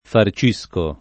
farcire